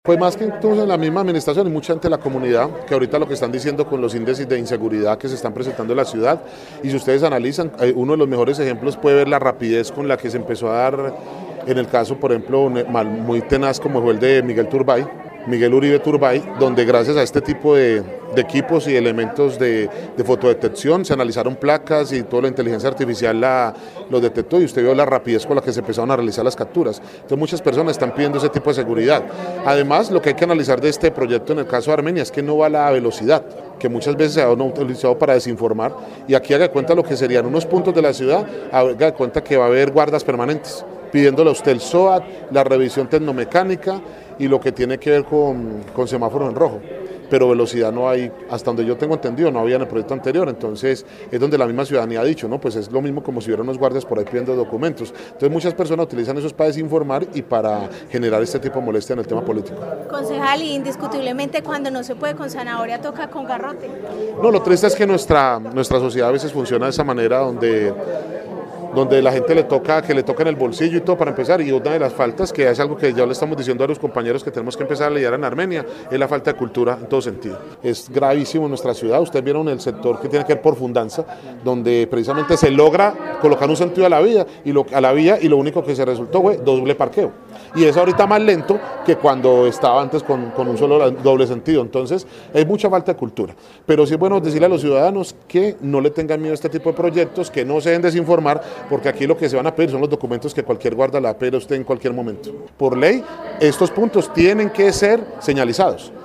Concejal Richard Gutierrez